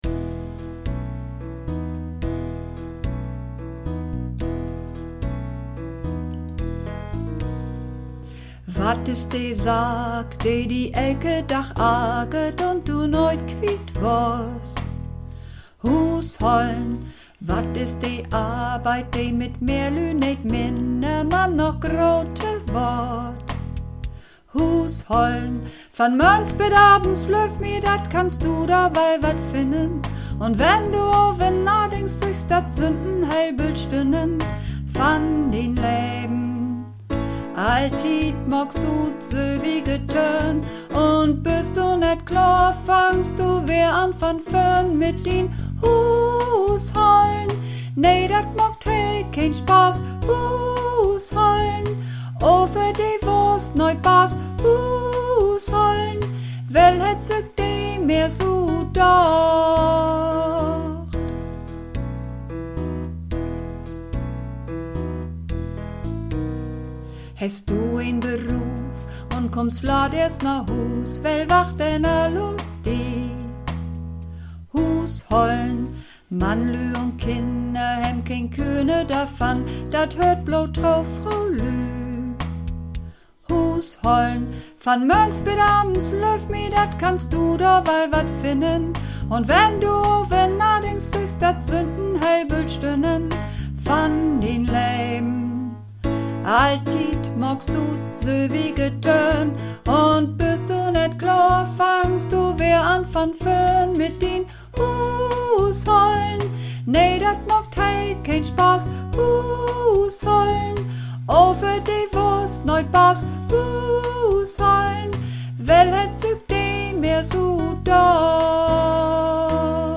Übungsaufnahmen - Huushollen
Huushollen (Hoch)